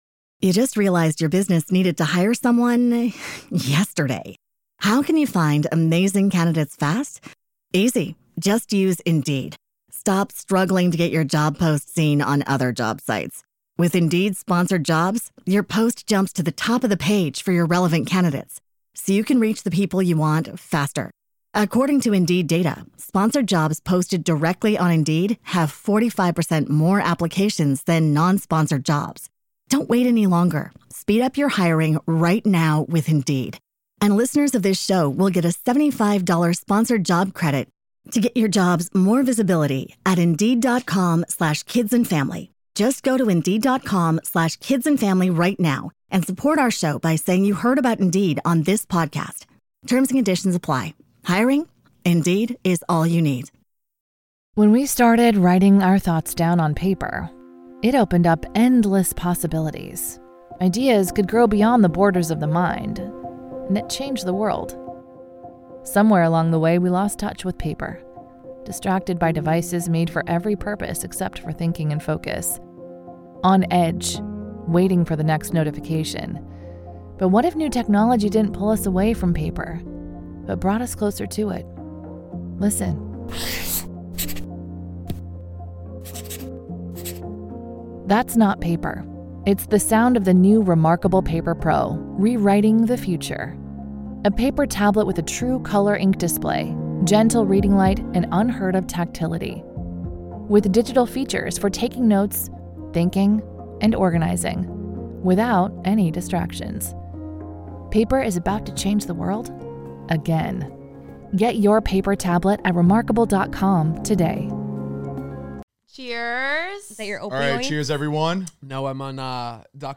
We read your dating horror stories, and boy do we absolutely SCREAM for you guys. From dating someone who tried to kidnap a mayor, to just overall f-boys… y’all really have gone through it.